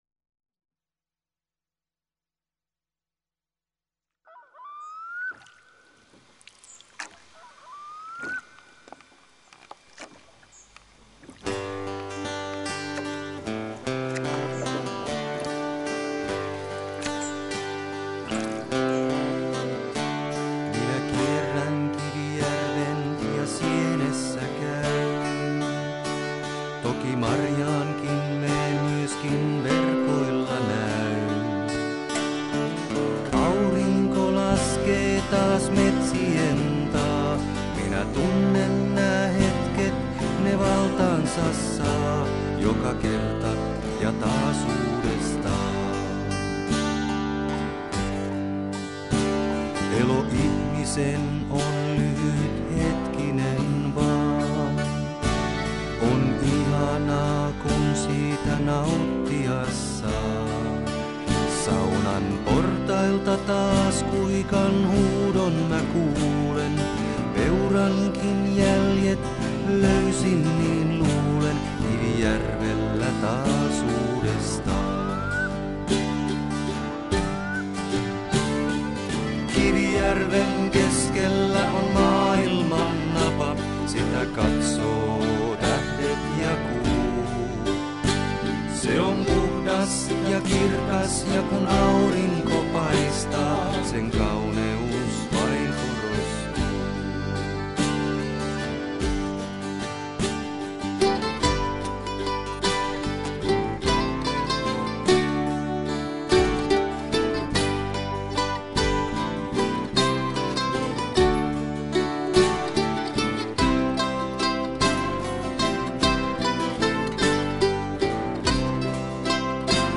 laulu ja kitara
percussion, äänitehosteet ja sovitus
kitarat ja mandoliini
haitari
taustalaulu